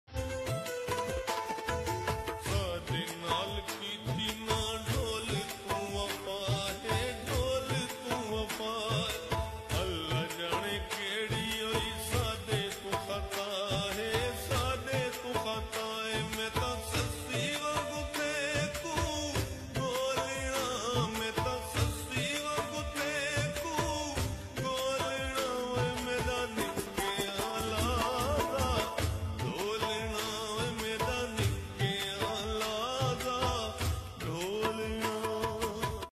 Song Slow&Reverb
𝐒𝐚𝐫𝐚𝐢𝐤𝐢 𝐒𝐨𝐧𝐠 𝐒𝐥𝐨𝐰 𝐑𝐞𝐯𝐞𝐫𝐛